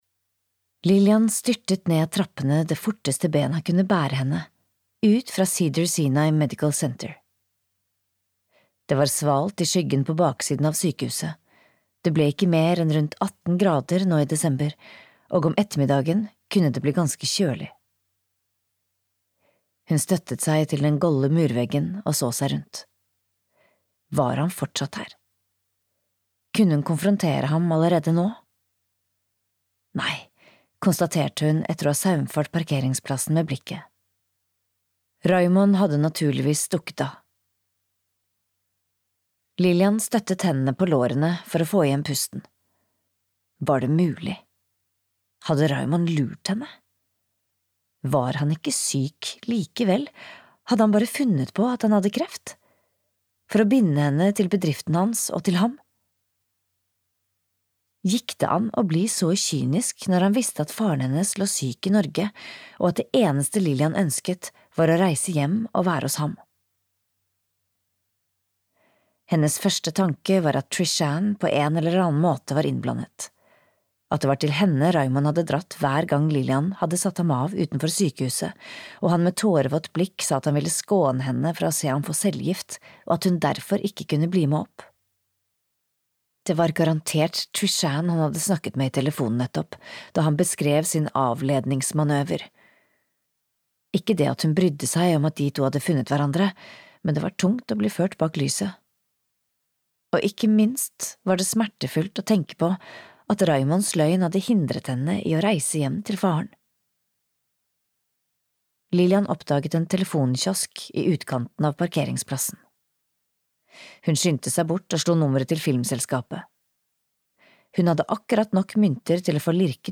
Sorti (lydbok) av Hege Løvstad Toverud